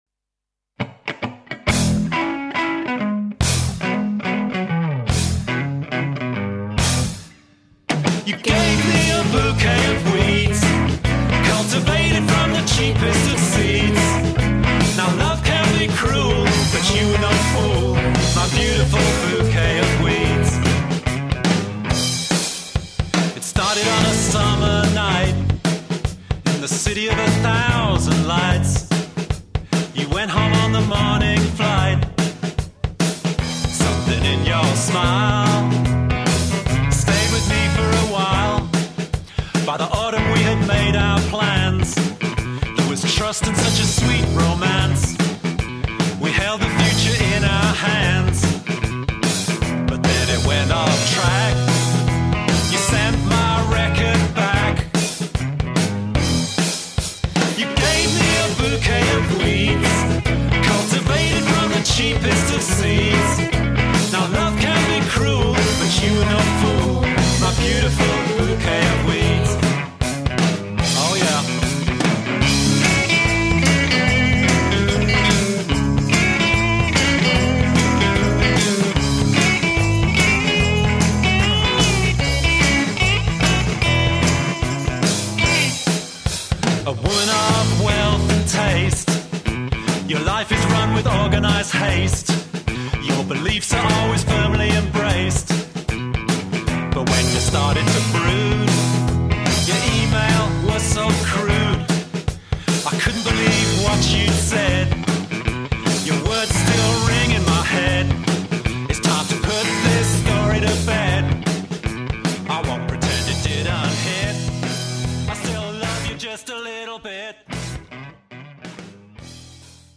a love song with an unusual sting in the tail